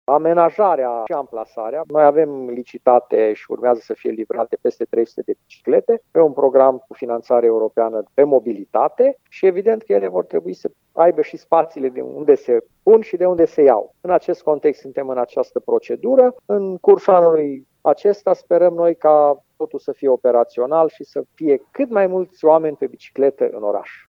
Acțiunea are loc în cadrul proiectul prin care administrația locală va pune la dispoziție, spre închiriere, câteva sute de biciclete, spune primarul Călin Bibarț.